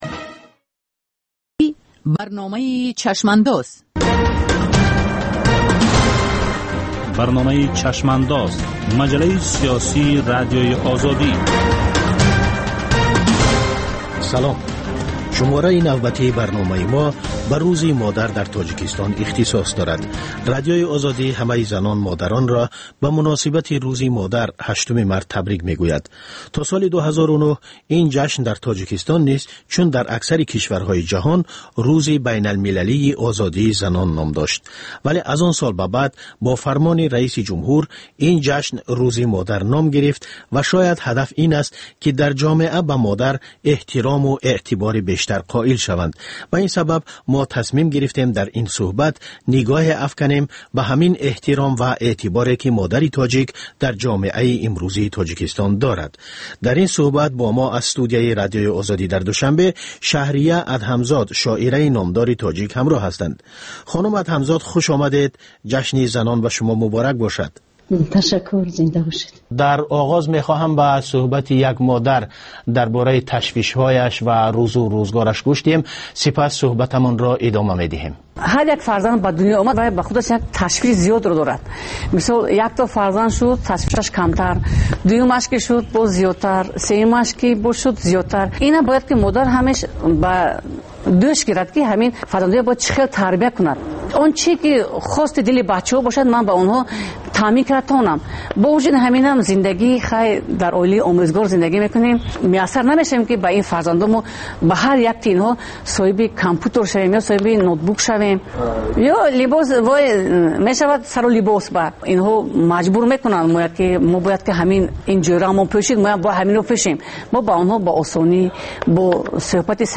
Нигоҳе ба таҳаввулоти сиёсии Тоҷикистон, минтақа ва ҷаҳон дар ҳафтае, ки гузашт. Гуфтугӯ бо сиёсатмадорон ва коршиносон.